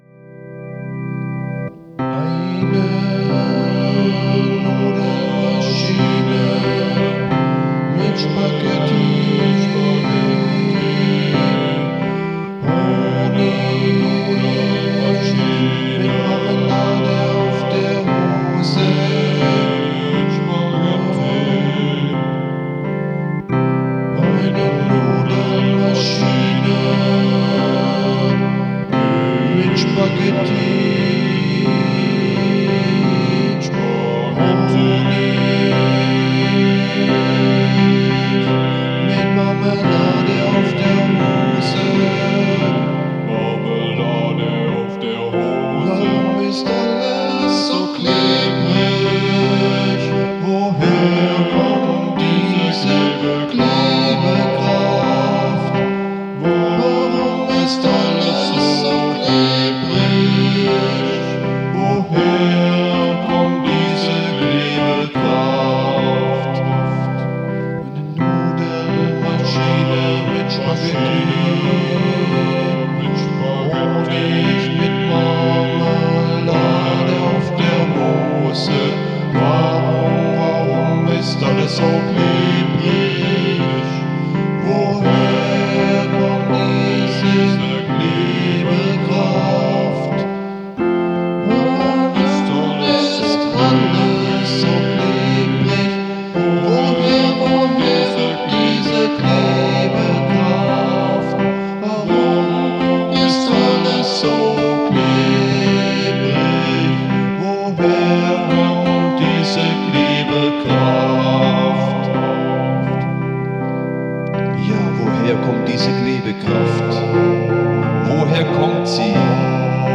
Comedy Choral.
Tempo: 45 bpm / Datum: 19.01.2015